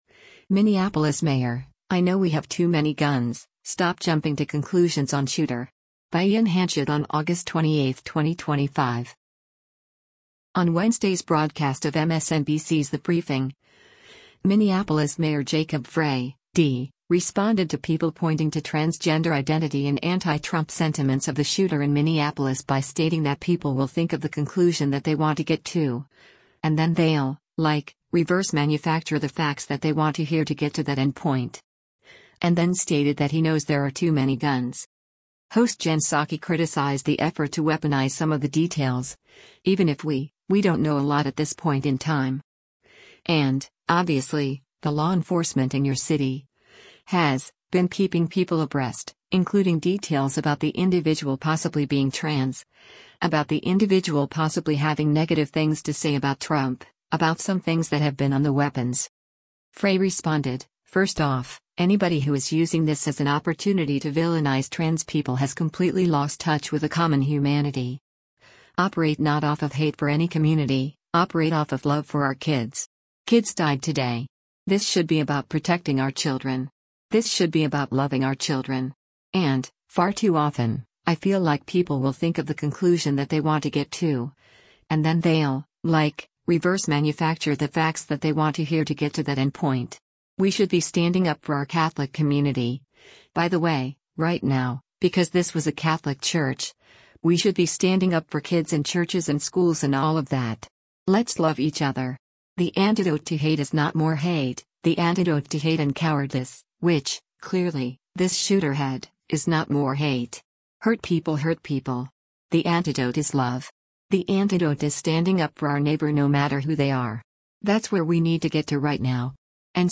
On Wednesday’s broadcast of MSNBC’s “The Briefing,” Minneapolis Mayor Jacob Frey (D) responded to people pointing to transgender identity and anti-Trump sentiments of the shooter in Minneapolis by stating that “people will think of the conclusion that they want to get to, and then they’ll, like, reverse manufacture the facts that they want to hear to get to that end point.” And then stated that he knows there are too many guns.